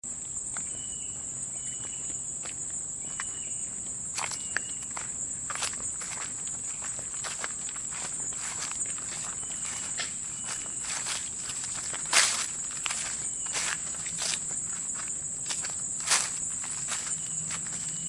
作者：Zoom h2n
标签： 鸟声 自然
声道立体声